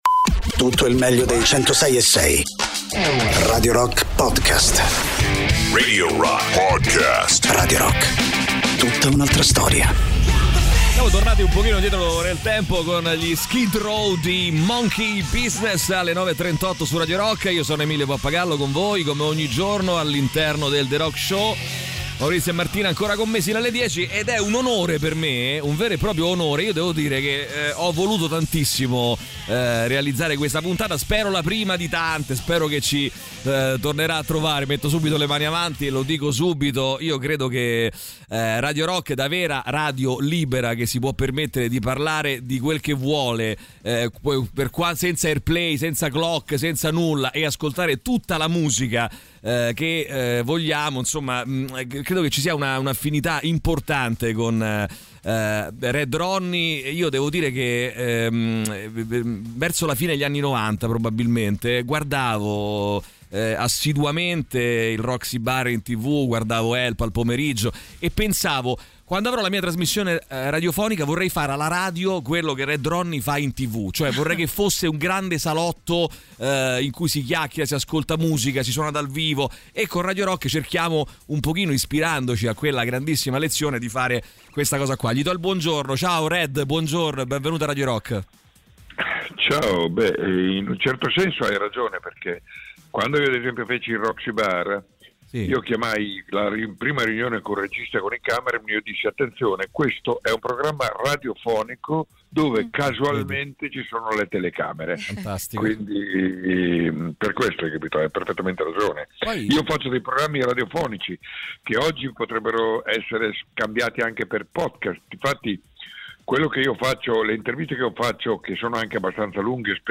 Interviste: Red Ronnie (12-02-25)